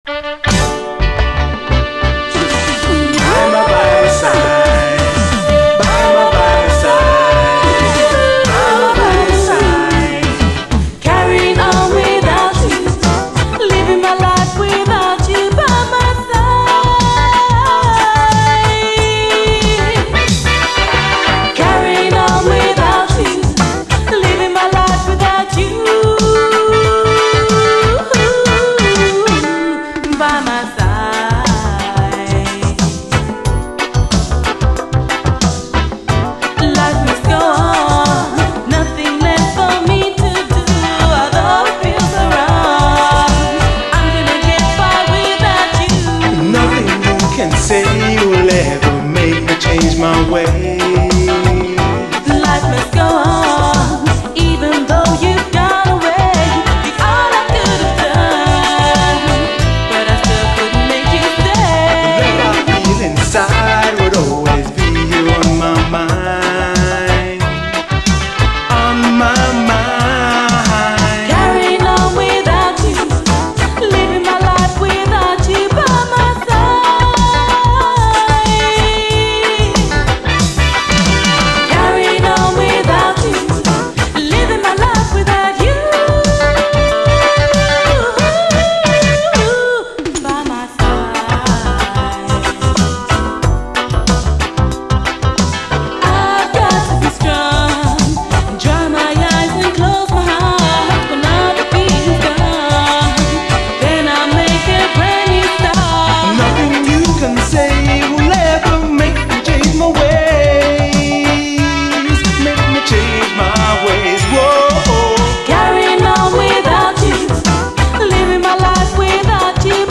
REGGAE
オブスキュア・キラーUKラヴァーズ〜ディスコ・レゲエ！溢れるポジティブ・ヴァイブス！